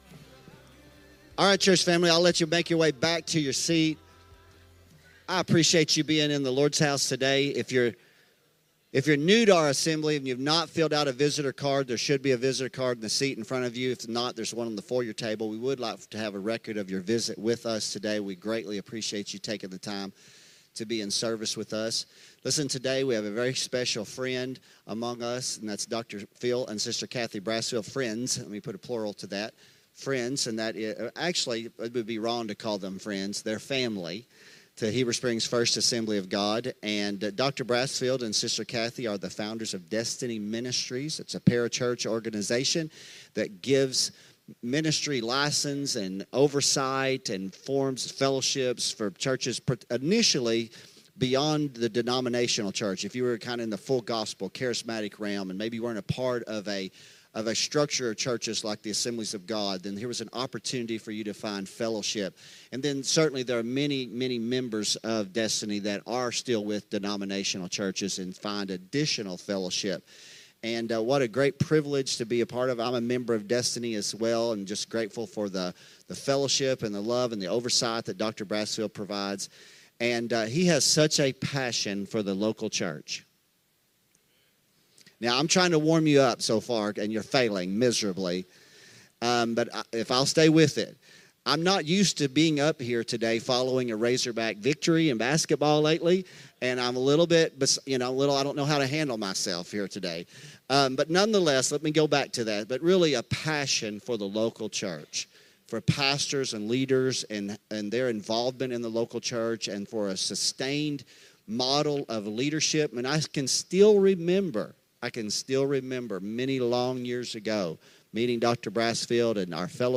Sermons | The Assembly Heber Springs